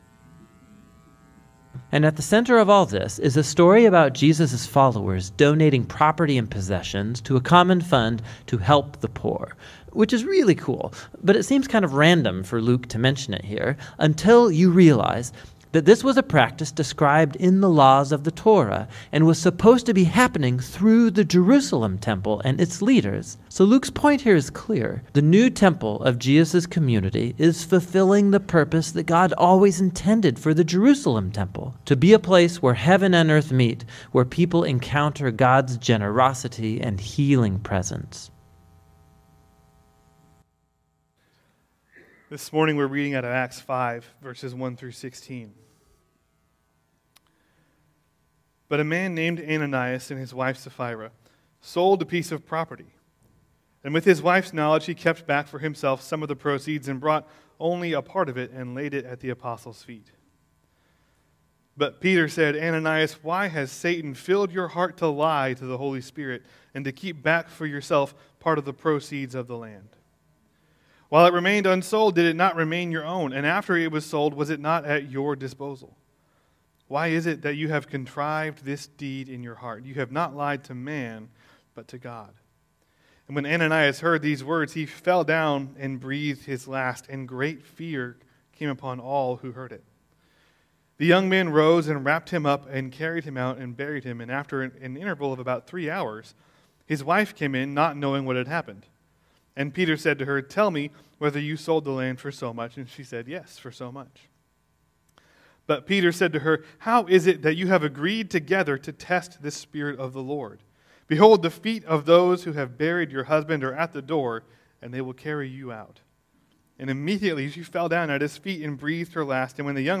Sermons | Asbury Methodist Church